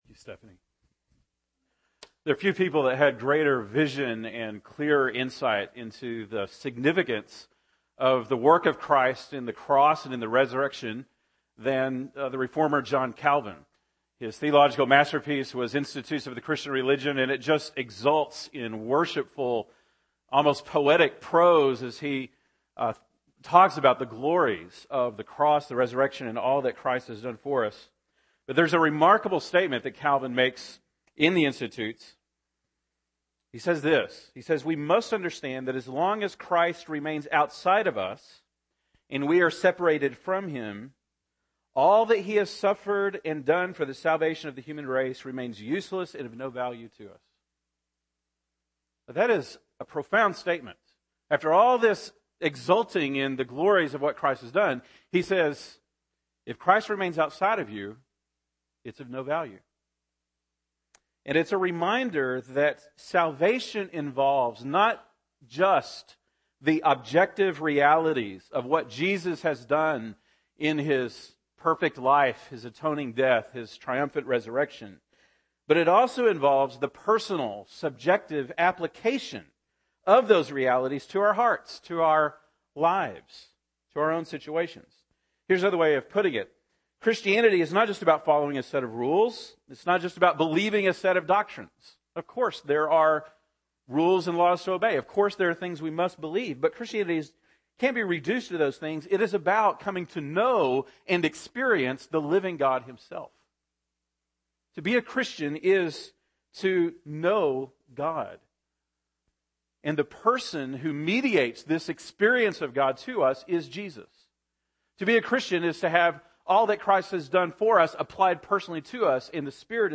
November 17, 2013 (Sunday Morning)